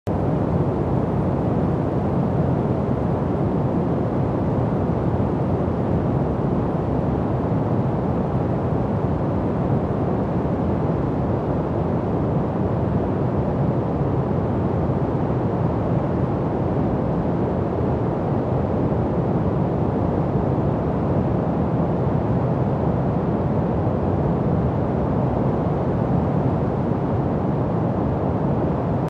💫 It’s the hush of green noise, the weight of brown noise, the gentle tap of raindrops — a cosmic orchestra that slows your racing thoughts and holds your nervous system in a soft embrace.